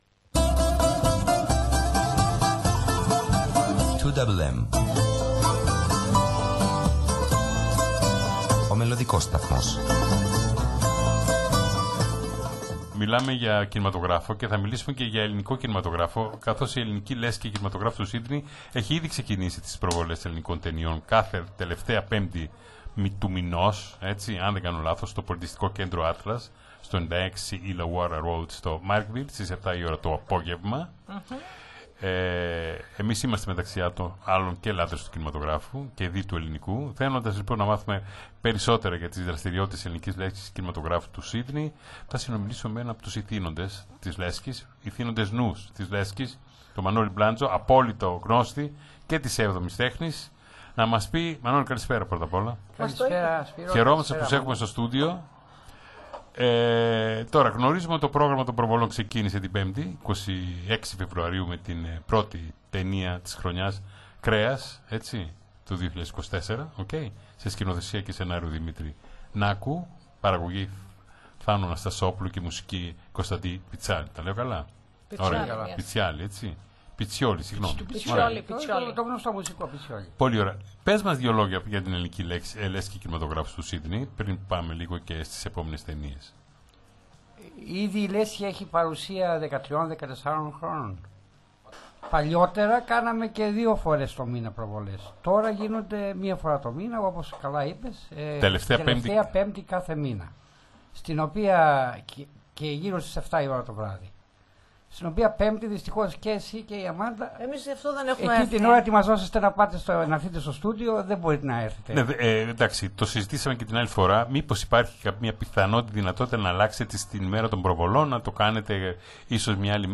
Συμμετείχε ζωντανά στην εκπομπή του ελληνόφωνου ραδιοσταθμού του Σίνδει, 2mm,” Νυκτερινοί Περίπατοι Ραδιοφώνου” της Πέμπτης 05/03/26,